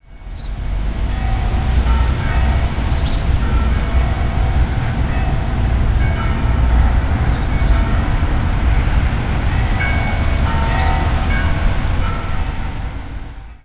Churchbells
I couldn't remember ever hearing so many church bells so clearly on a downtown street corner (Queen and King streets in Kitchener).
I recorded the bells from that church on a soundwalk Sunday morning, May 17.
bchbells.aiff